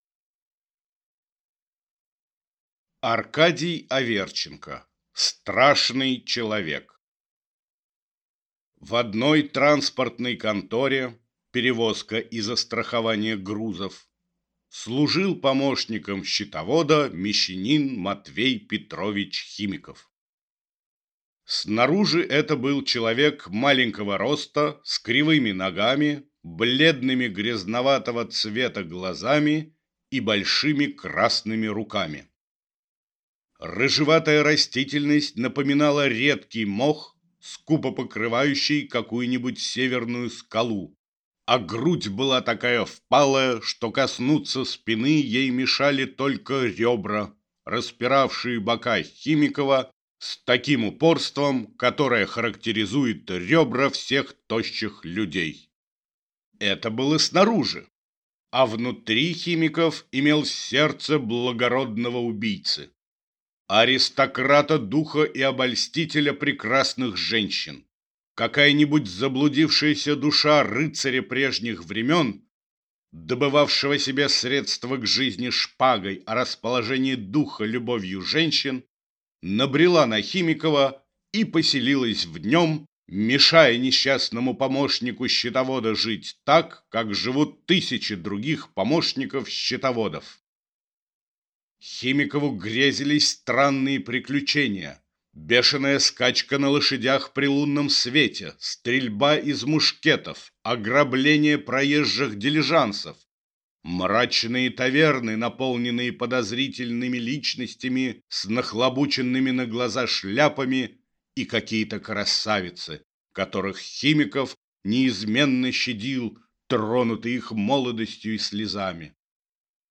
Аудиокнига Страшный человек | Библиотека аудиокниг